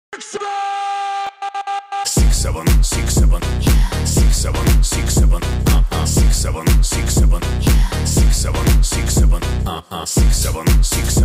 67 Gazan Sound Effect: Distinctive Audio Cue
This is the '67 Gazan' sound effect, a unique audio cue often used for notifications, alerts, or as a distinct identifier in various media.